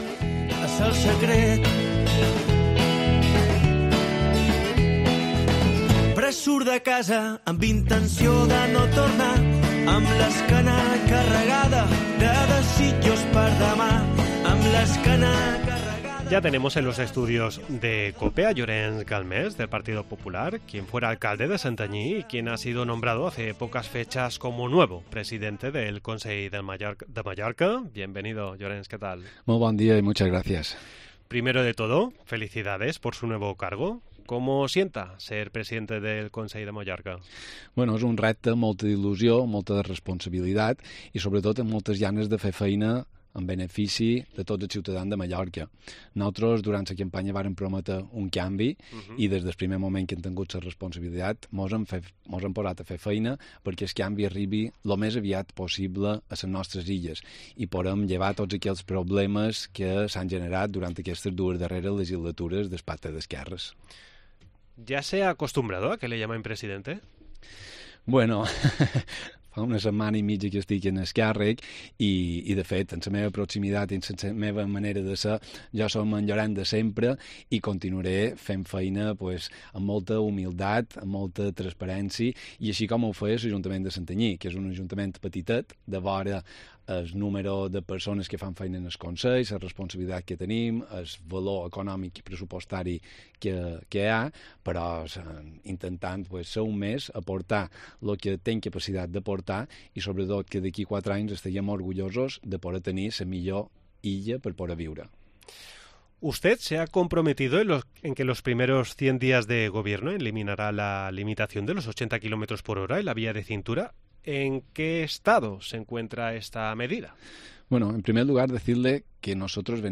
Hoy visita los estudios de cope Mallorca Llorenç Galmés del Partido Popular, quien fuera alclande Santanyí, y que ha sido nombrado hace pocas fechas como nuevo presidente del Consell de Mallorca.